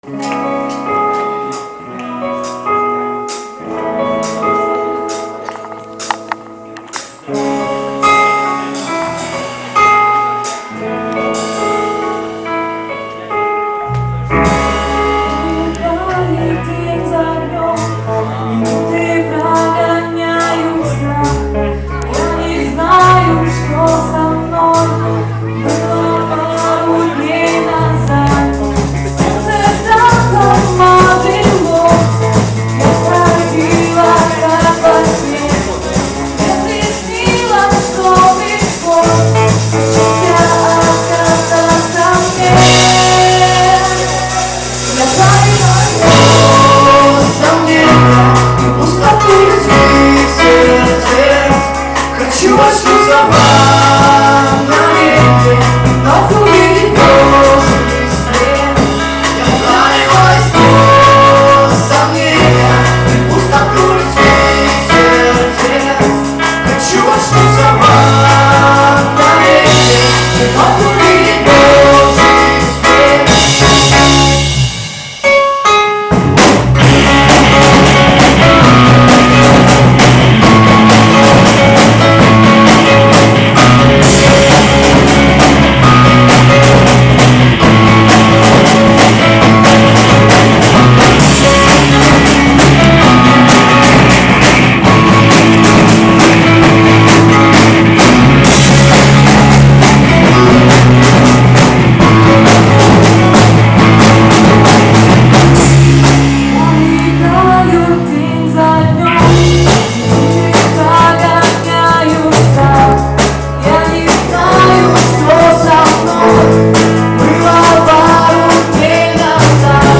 новой песни с концерта... правда за качество не ручаюсь))))